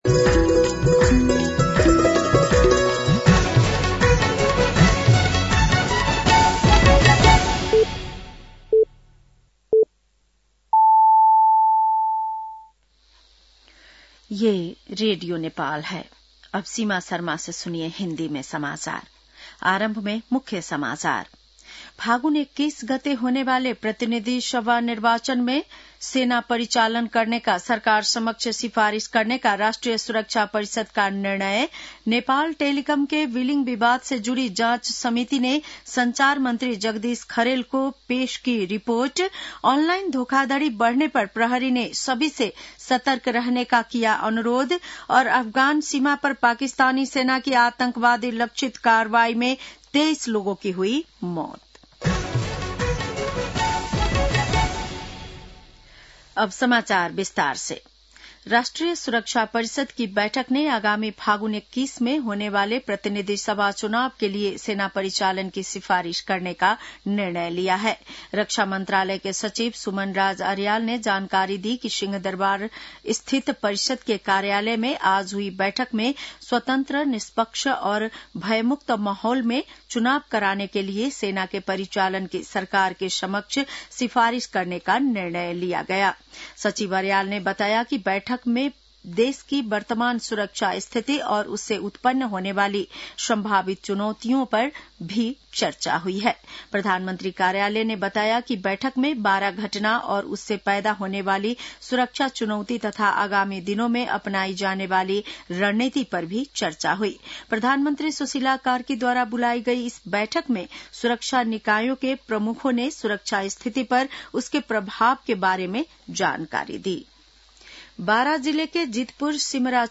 बेलुकी १० बजेको हिन्दी समाचार : ४ मंसिर , २०८२
10-PM-Hindi-NEWS-8-4.mp3